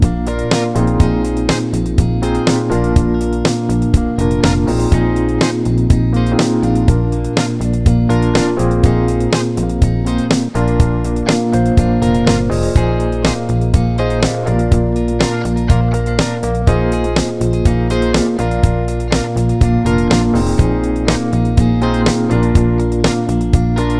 Minus Guitar Solo Pop (1970s) 3:31 Buy £1.50